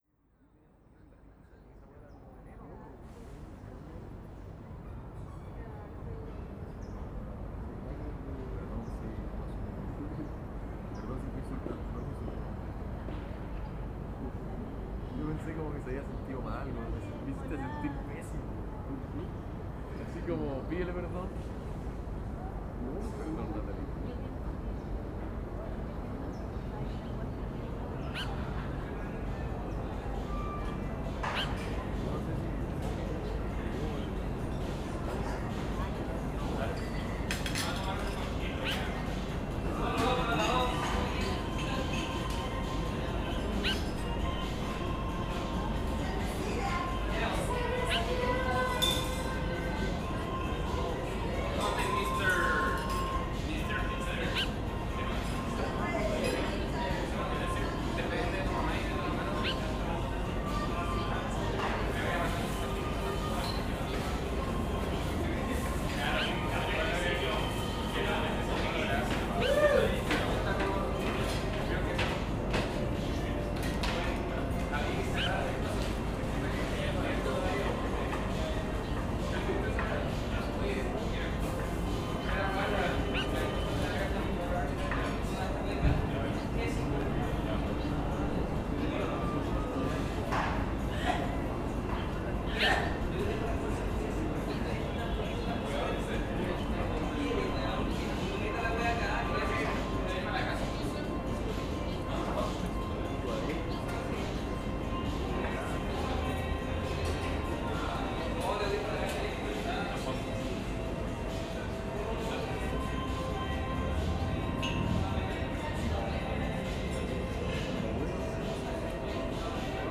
Pendant mes dernières semaines à Santiago, j’ai religieusement enregistré les sons de mes parcs et cafés préférés de la ville, avec une certaine angoisse et une nostalgie devancée. Avec quelques pièces en tête et celles qui jouaient dans les différents lieux, un mélange anxiogène s’est créé, parce que les retours et les départs sont toujours difficiles, malgré les oiseaux qui, obstinément, nous tiennent compagnie.
[Sons enregistrés pendant les dernières semaines de mars 2019 dans différents endroits publics de Santiago : PortaCafé (Bellas Artes / Lastarria) + Café Triciclo (Baquedano) + Café del GAM (Lastarria) + Café Républicano (Barrio Italia) + Café Original Coffee Roasters (Bustamante) + Parque San Borja (Centro) + Parque Forestal + Parque Bustamante + Échantillon de ‘Dime’ de Catana tiré de Queen C (2018, auto-produit) + Échantillon de ‘Nothing compare 2 U’ de Prince (2018, NPG Records Inc. / Warner Brothers Records) + Échantillon de ‘Smalltown Boy’ de Bronski Beat tiré de The Age of Consent (1998, London Music Stream Ltd. / ADA UK)]